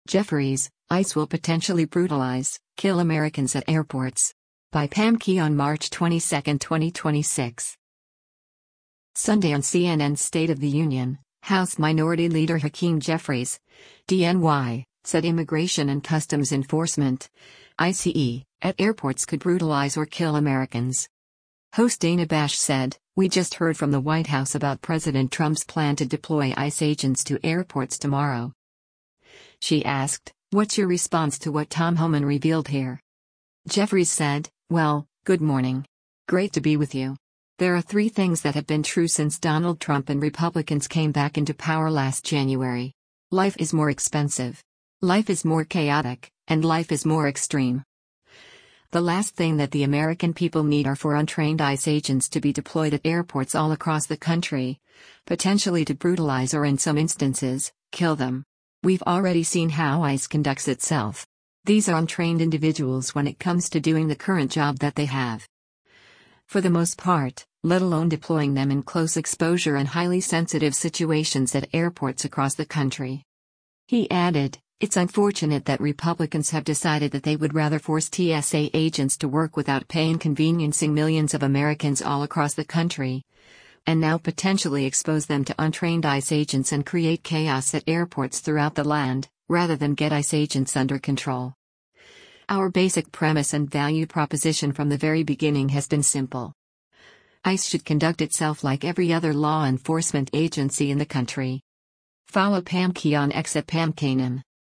Sunday on CNN’s “State of the Union,” House Minority Leader Hakeem Jeffries (D-NY) said Immigration and Customs Enforcement (ICE) at airports could “brutalize” or “kill” Americans.
Host Dana Bash said, “We just heard from the white House about President Trump’s plan to deploy ICE agents to airports tomorrow.”